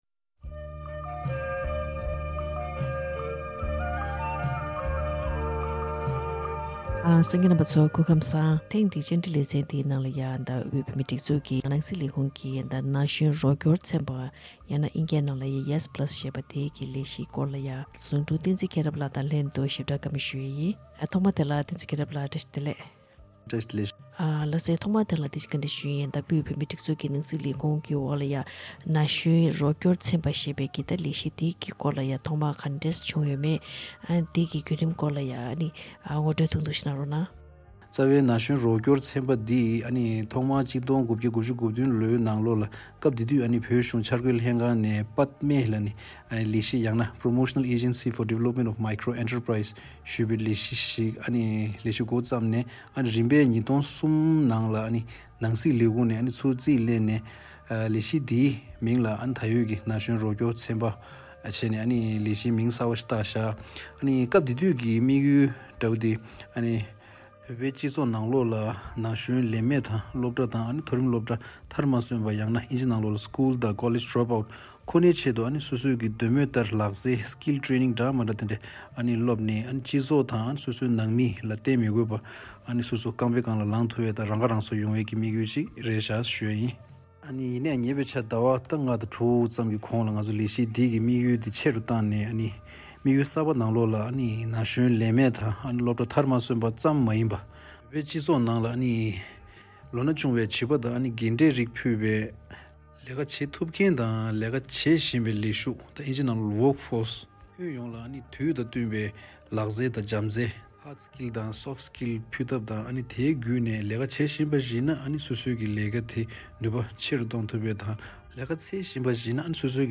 བཀའ་འདྲི་ཞུས་པ་ཞིག་གསན་གནང་གི་རེད།